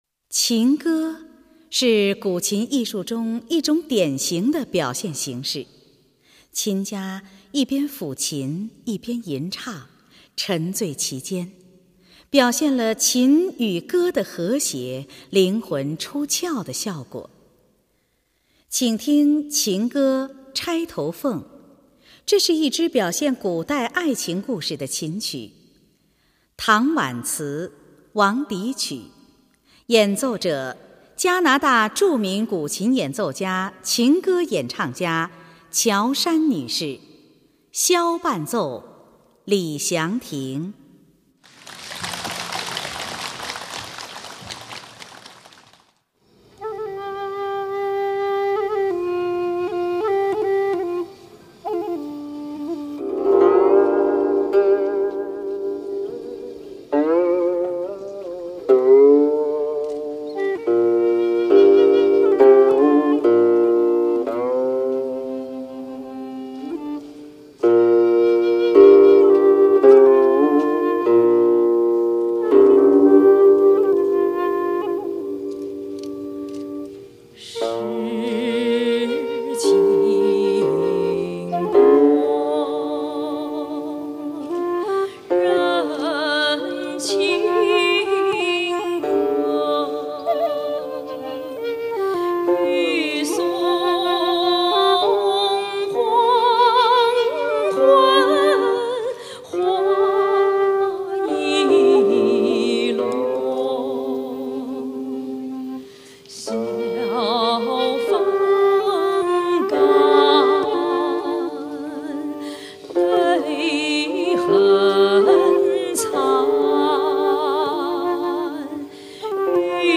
古琴
本专辑为2006成都·中国古琴国际艺术节暨文君文化节期间，11月24日成都杜甫草堂大雅堂前名家演奏音乐会实况。
琴歌
箫